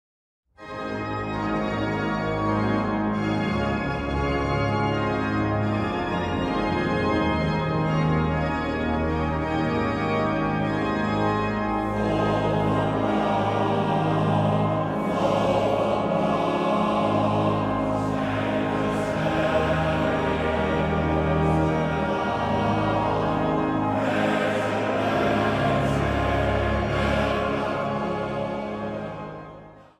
Instrumentaal
Zang
Engelse melodie